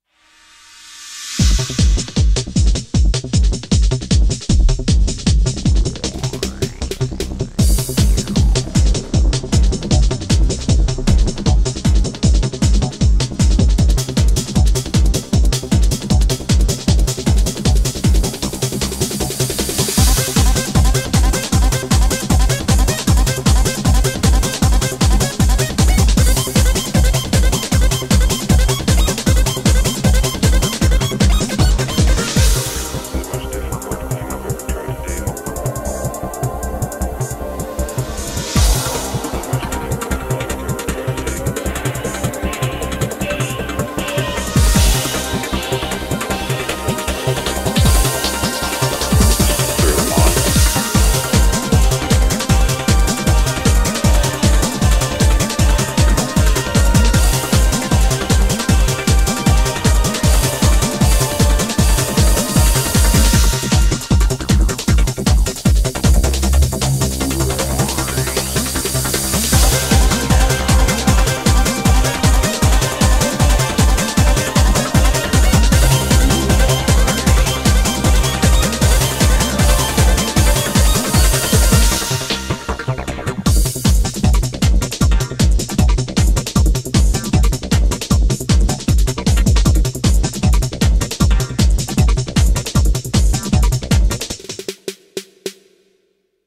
BPM155
Comments[GOA TRANCE]